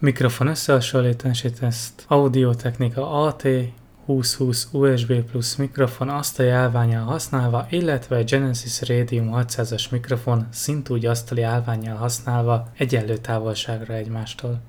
Hangminőség teszt: összehasonlítás